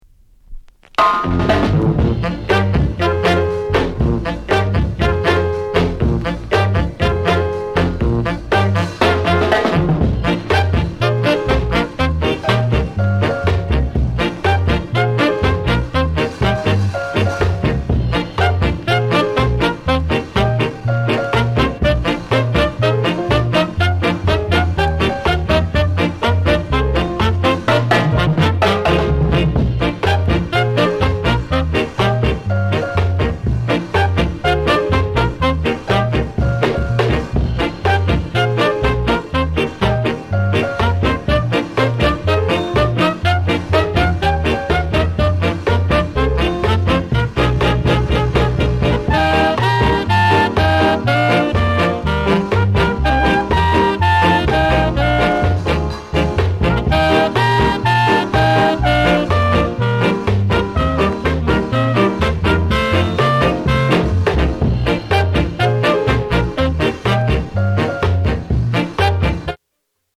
AUTHENTIC SKA INST